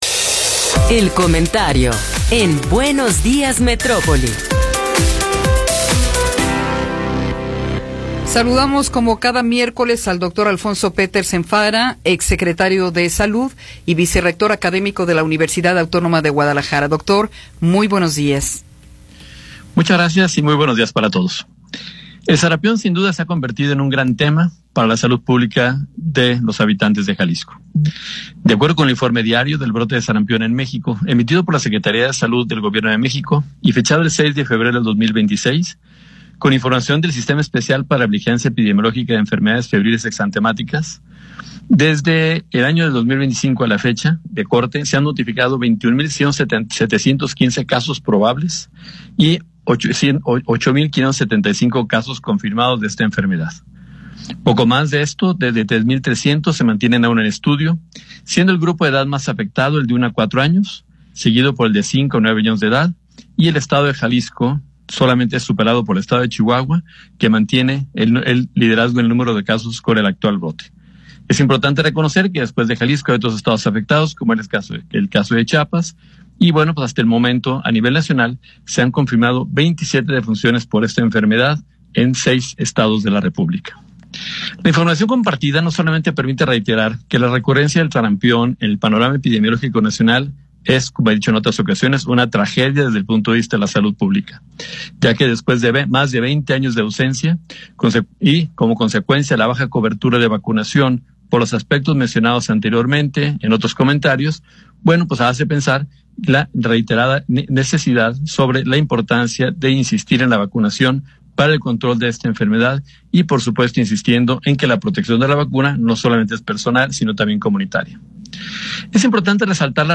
Comentario de Alfonso Petersen Farah